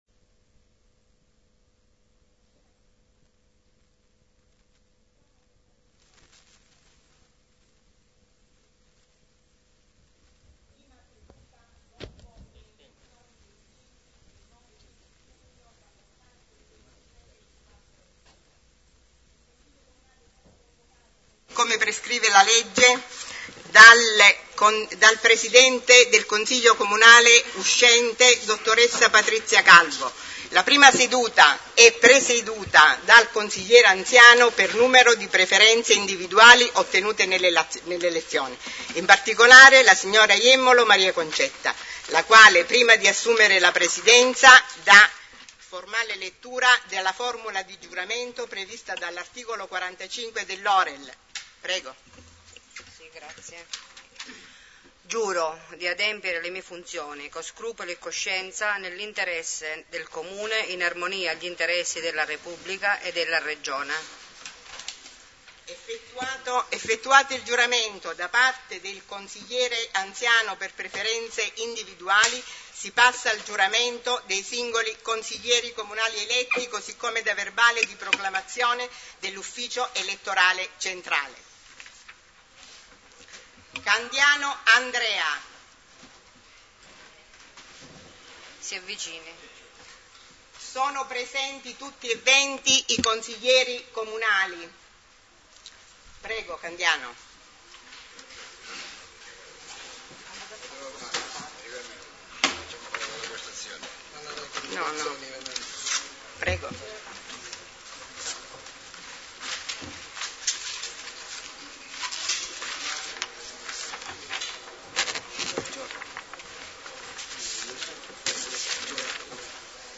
1 consiglio comunale -elezione presidente e vice presidente 22.07.2013